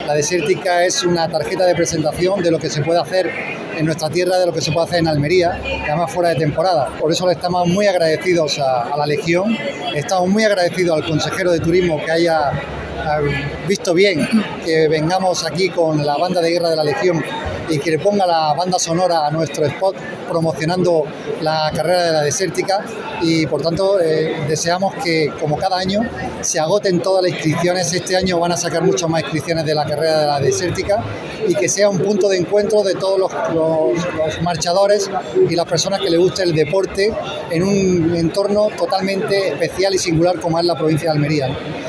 23-01-fitur-desertica-presidente.mp3